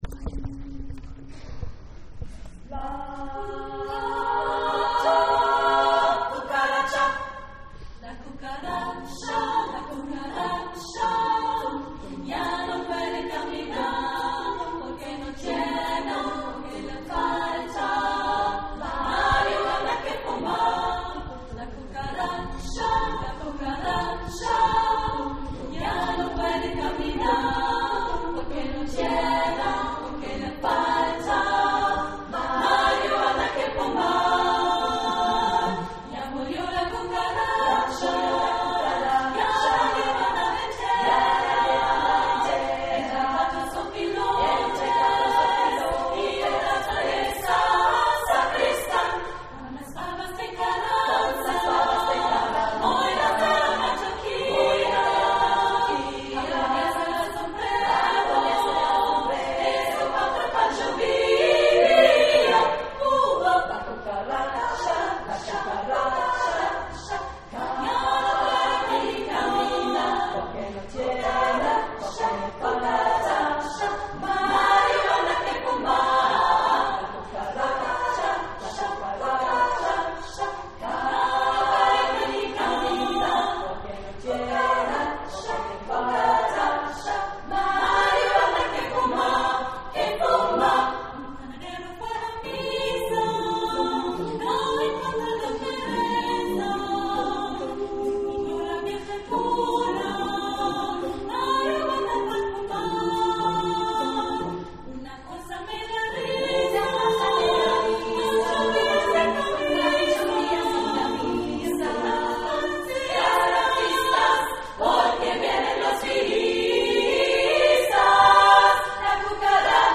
Mex. VL, a cappella
durchkomponiert